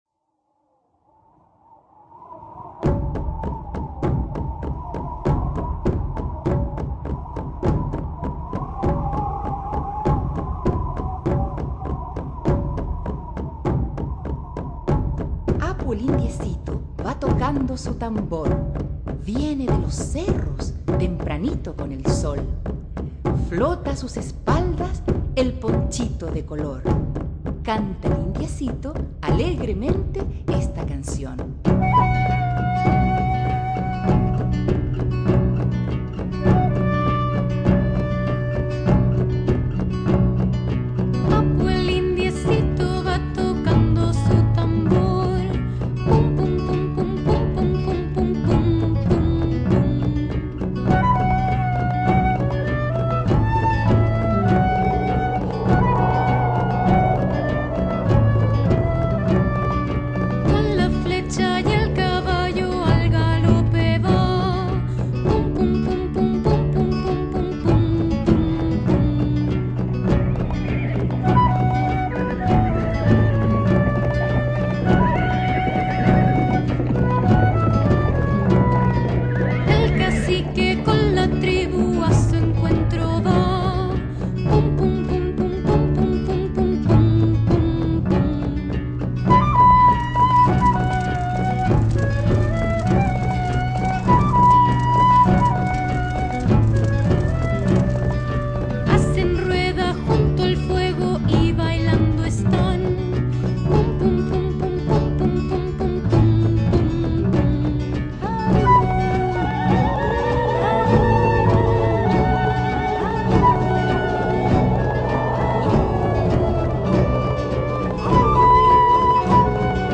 Música infantil
Canciones